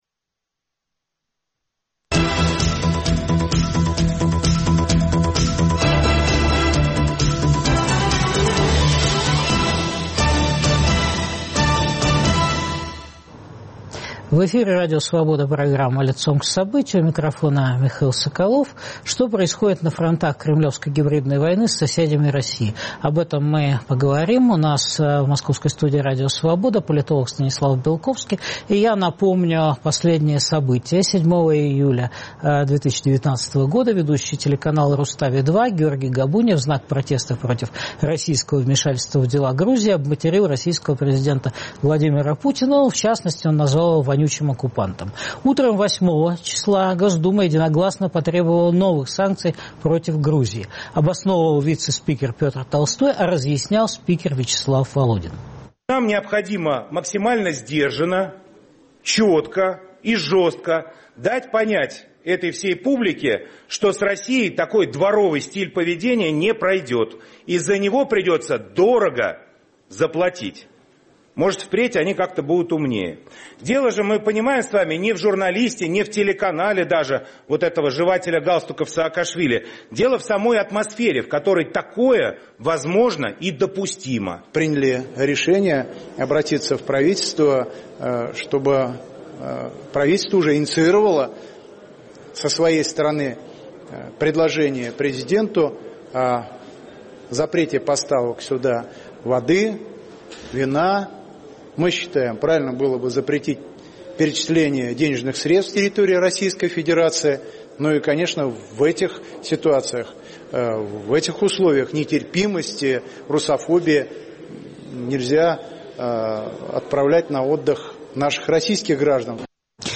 Стратегию Кремля обсуждают политологи Станислав Белковский, Олесь Доний (Киев).